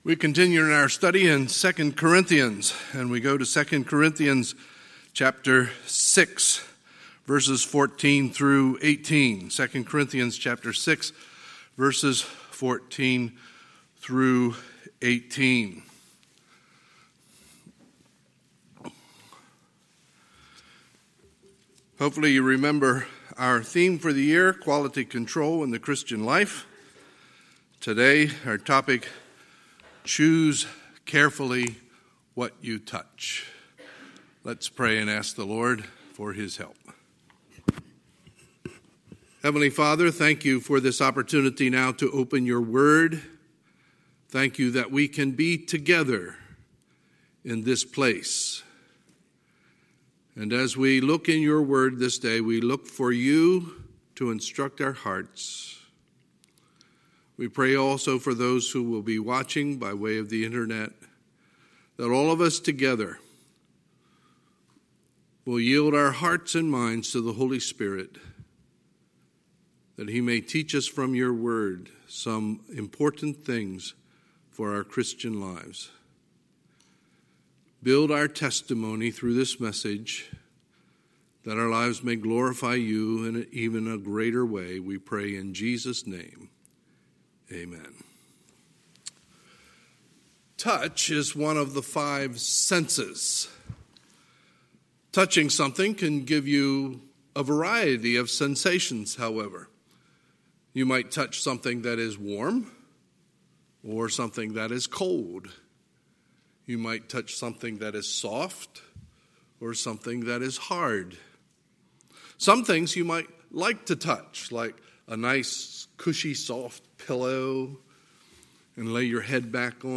Sunday, May 31, 2020 – Sunday Morning Service